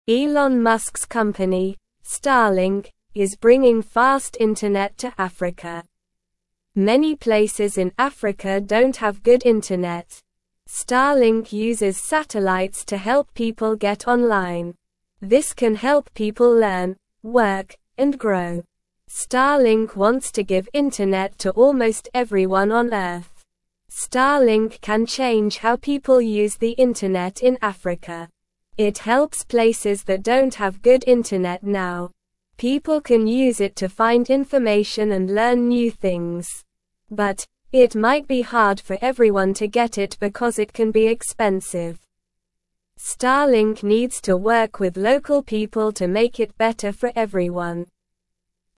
Slow
English-Newsroom-Beginner-SLOW-Reading-Starlink-brings-fast-internet-to-Africa-to-help-people.mp3